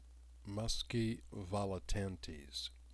This plays the word pronounced out loud.